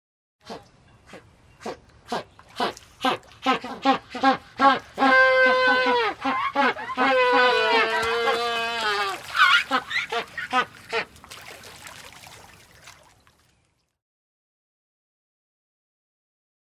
animal
Magellanic Penguins Small Group Braying 3